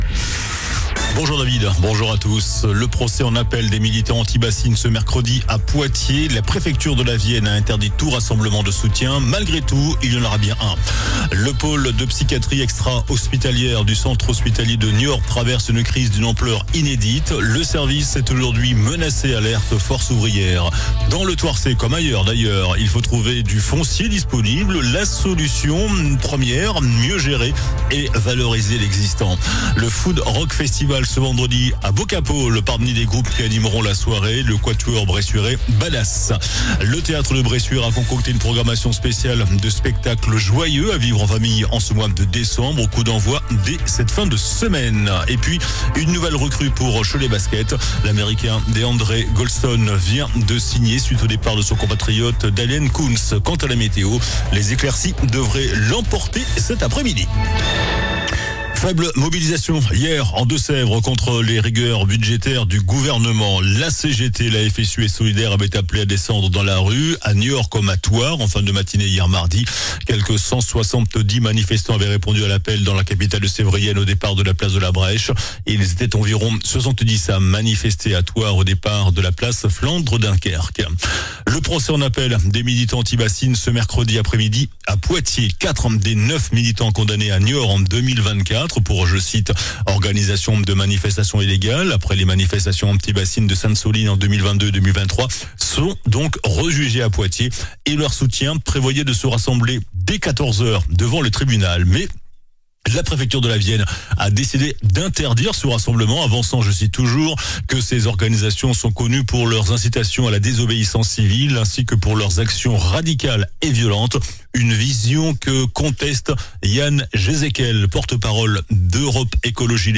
JOURNAL DU MERCREDI 03 DECEMBRE ( MIDI )